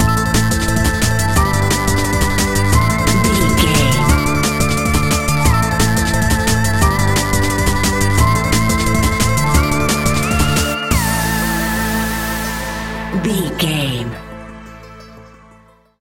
royalty free music
Aeolian/Minor
Fast
aggressive
powerful
dark
driving
energetic
epic
groovy
drum machine
synthesiser
electronic
sub bass
synth leads
synth bass